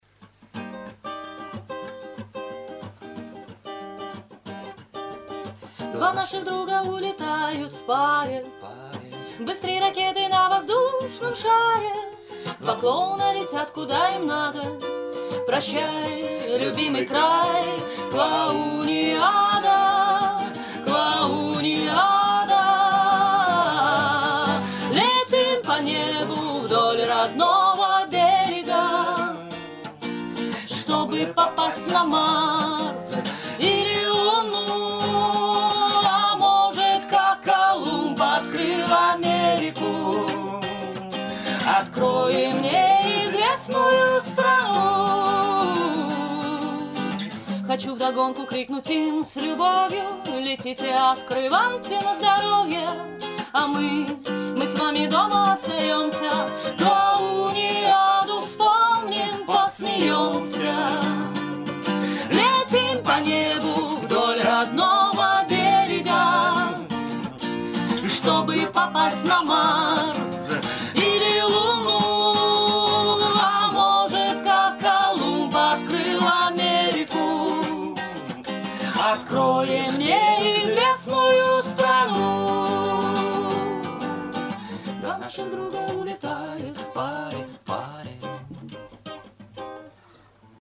Треки, исполненные вживую в Доме радио: